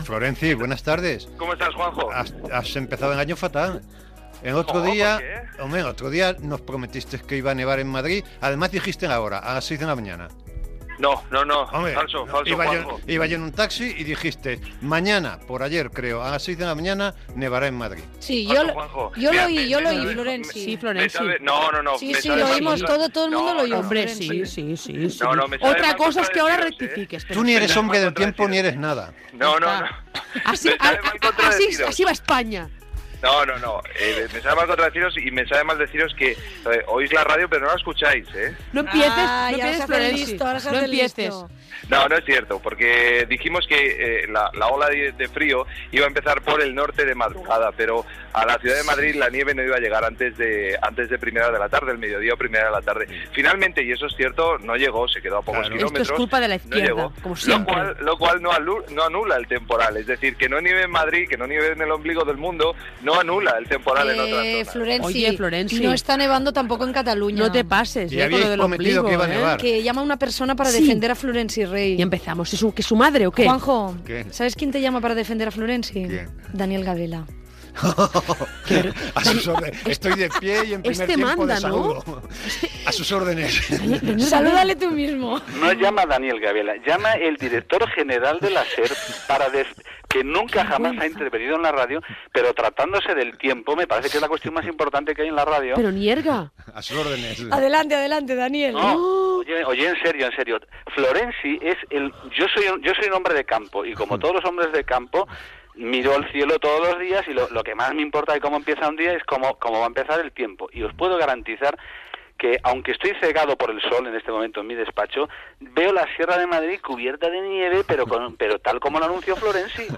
Entreteniment
Extret del programa "Entre tiempos" de la Cadena SER emès el 14 d'abril del 2019 i publicat al web de la Cadena SER.